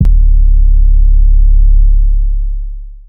sizzle no drop.wav